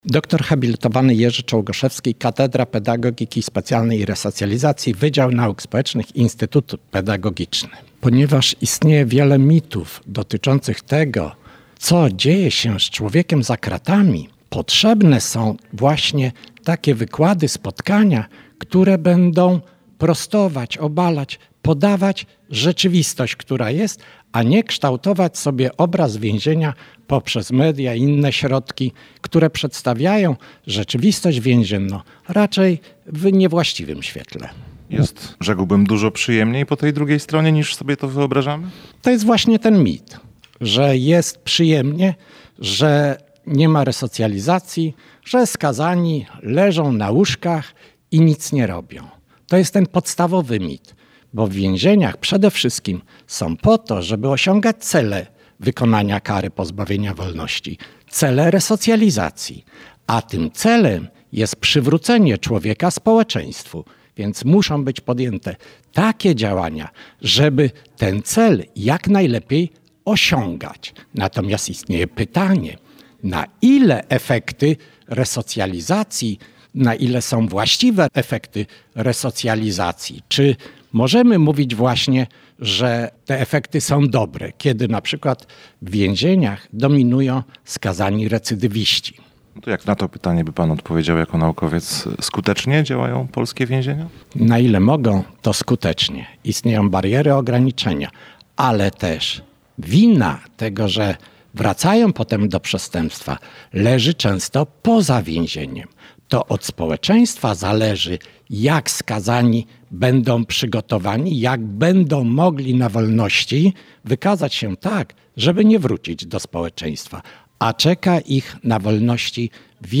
Rozmawiał z nim także nasz reporter.